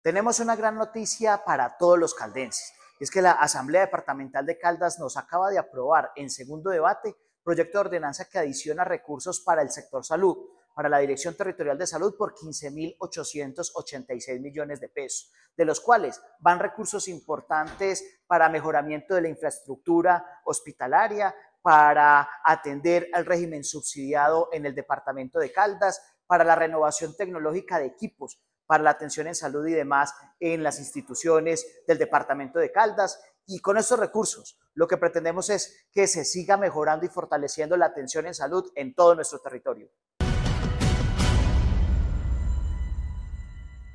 Jhon Alexander Alzate Quiceno, secretario de Hacienda de Caldas.